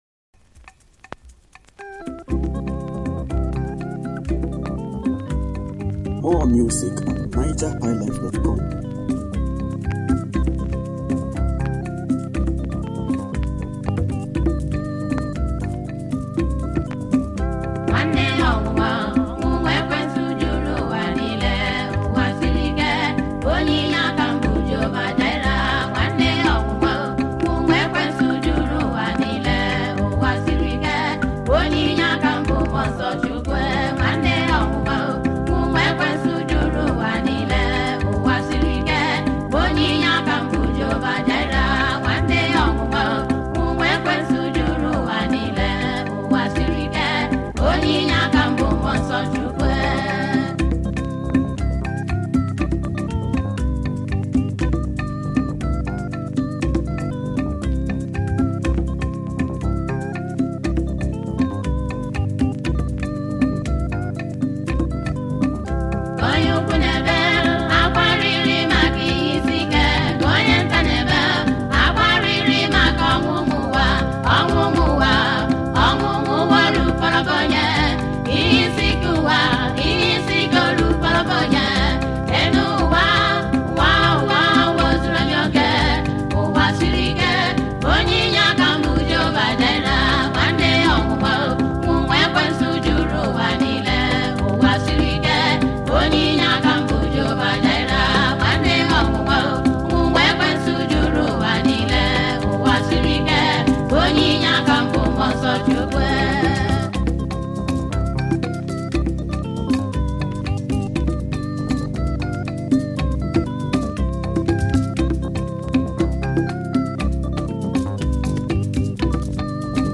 Home » Gospel
Igbo Gospel music